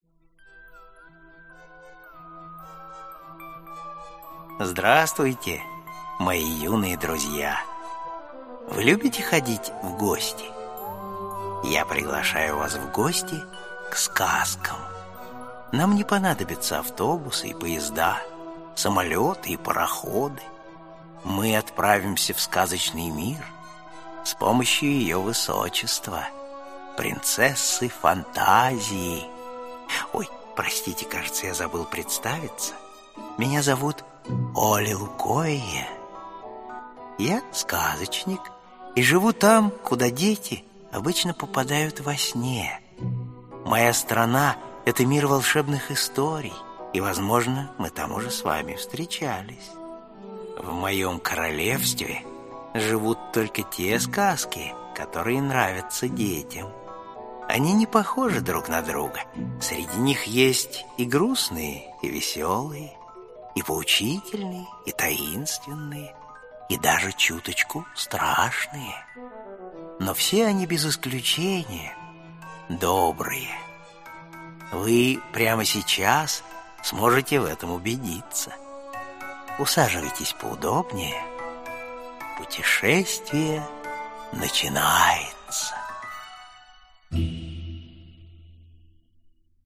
Аудиокнига Али-Баба и сорок разбойников | Библиотека аудиокниг
Aудиокнига Али-Баба и сорок разбойников Автор Эпосы, легенды и сказания Читает аудиокнигу Вячеслав Гришечкин.